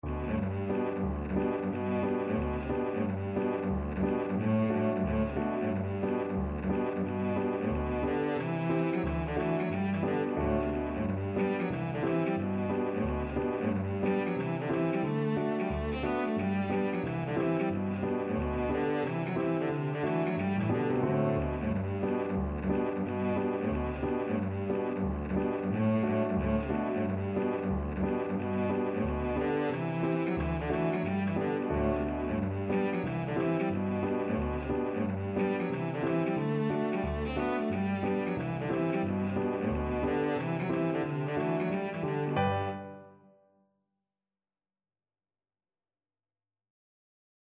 Cello version
2/2 (View more 2/2 Music)
G major (Sounding Pitch) (View more G major Music for Cello )
Traditional (View more Traditional Cello Music)